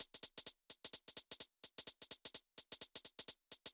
VFH3 128BPM Resistance Kit 7.wav